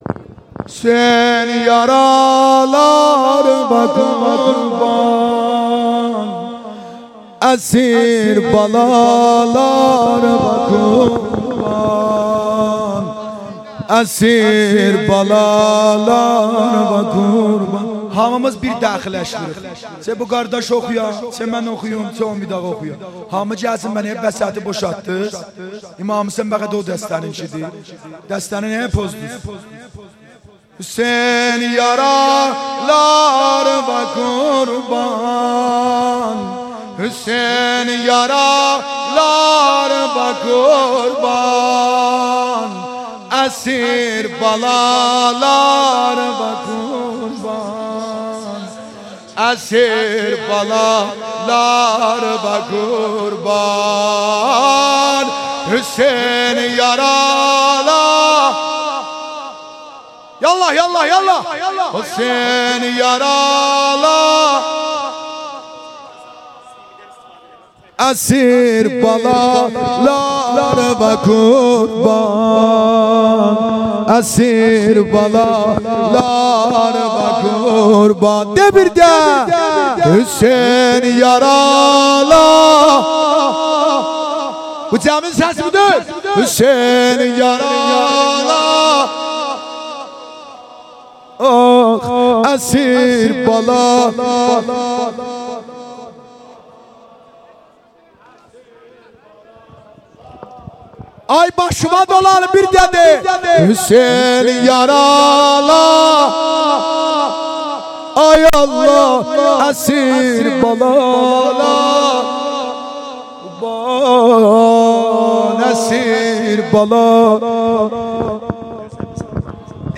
عصر عاشورا محرم 98 - سینه زنی
در جوار شهدای گمنام